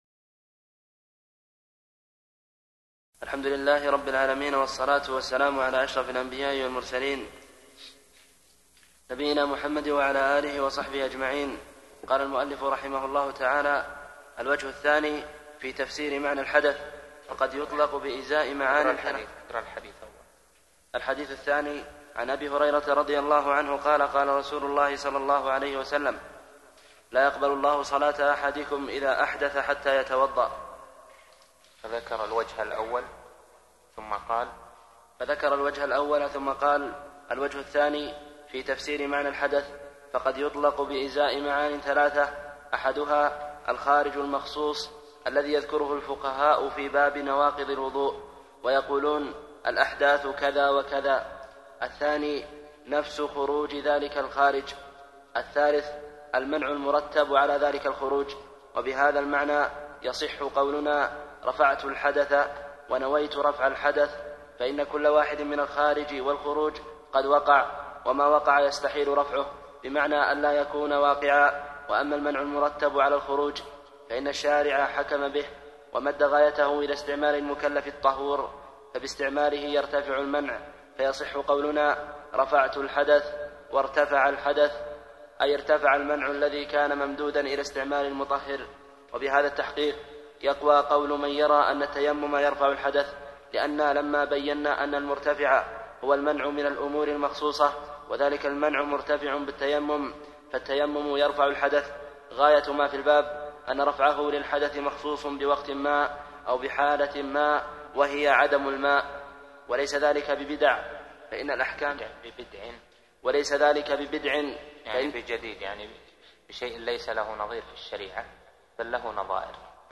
عمدة الأحكام في معالم الحلال والحرام عن خير الأنام شرح الشيخ صالح بن عبد العزيز آل الشيخ الدرس 3